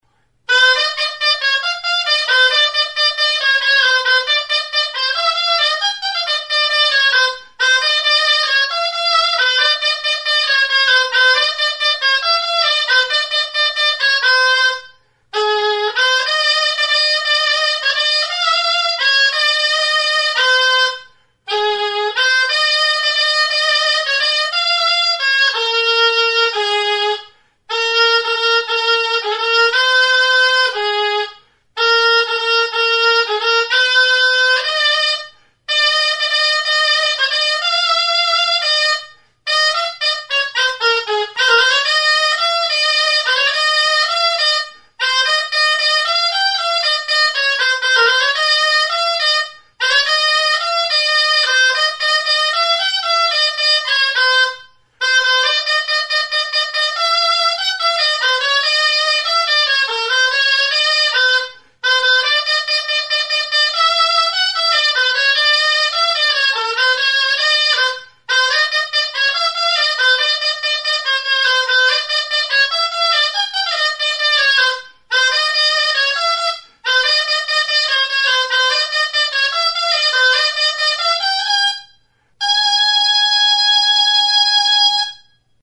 Aerófonos -> Lengüetas -> Doble (oboe)
Grabado con este instrumento.
Mihi bikoitzeko soinu-tresna da.
Do brillante tonuan dago.